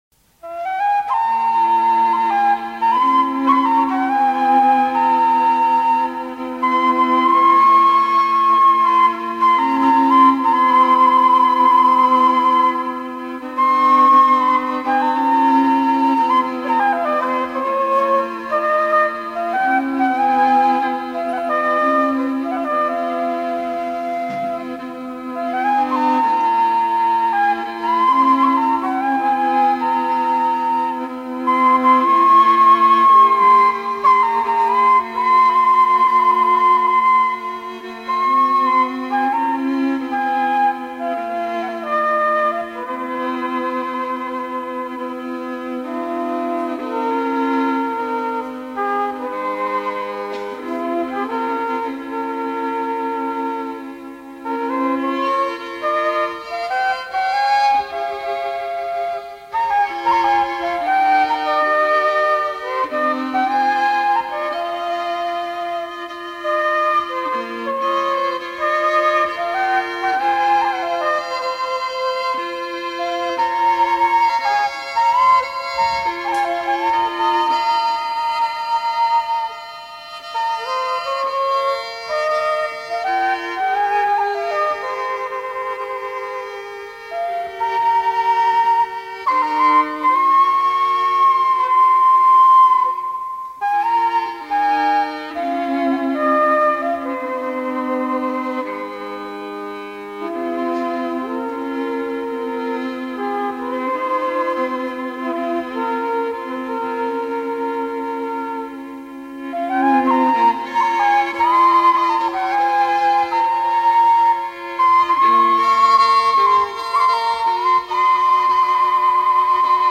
Slow air
Pièce musicale éditée